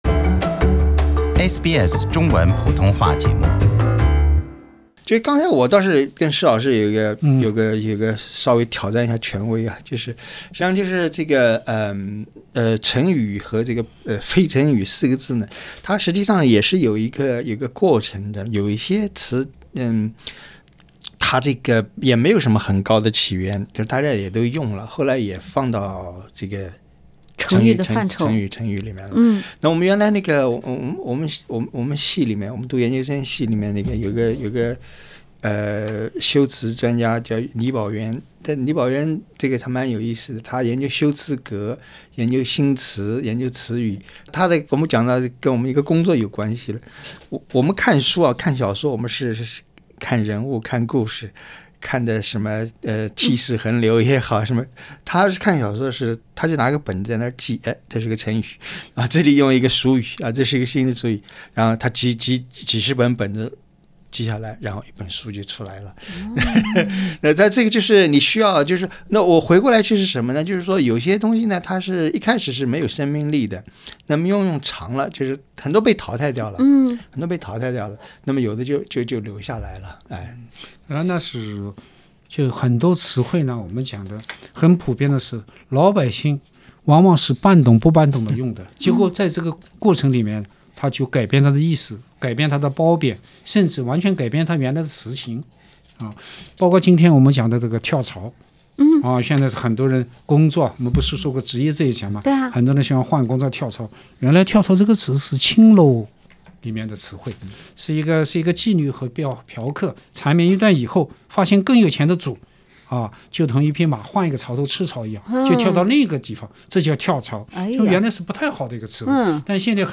对话中，文化苦丁茶三人组针对“抬杠”这个俗语，追根溯源、活灵活现地进行了阐释。